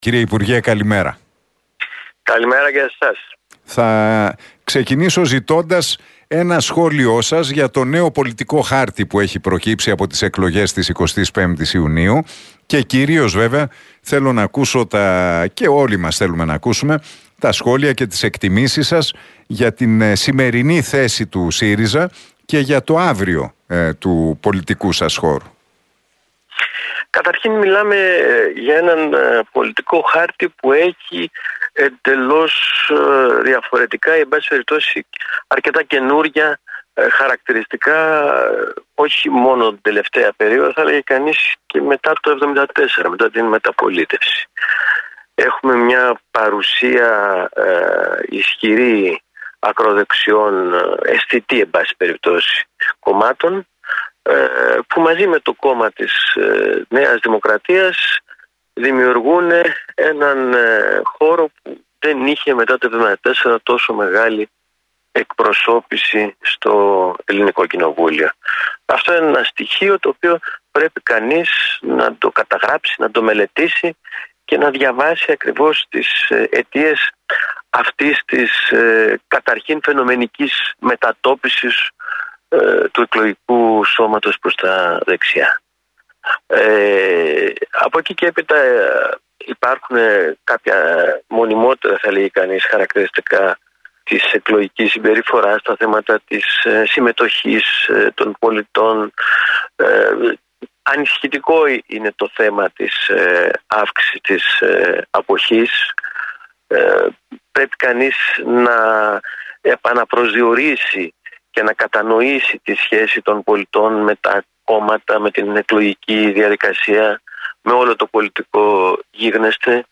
Ο πρώην βουλευτής του ΣΥΡΙΖΑ, Πάνος Σκουρλέτης, παραχώρησε συνέντευξη στον Realfm 97,8 και στην εκπομπή του Νίκου Χατζηνικολάου.